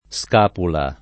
Sk#pula]: gli piantò a tradimento fra le due scapule un pugnale [